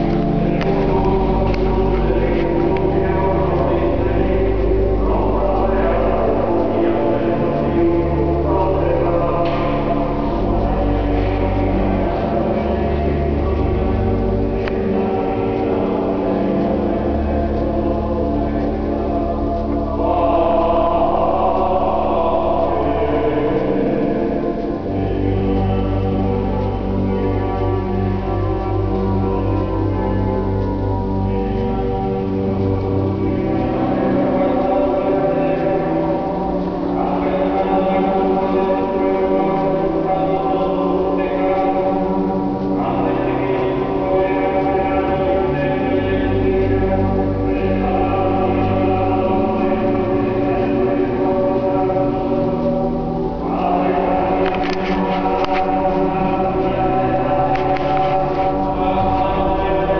Egyszer csak gregorián ének csendült a mecset falai között.